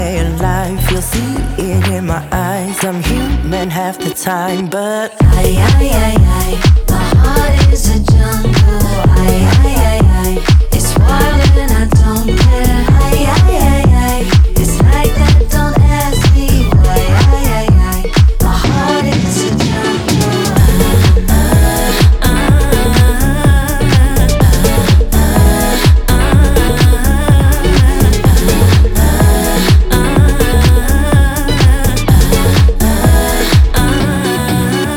Скачать припев
2025-03-13 Жанр: Поп музыка Длительность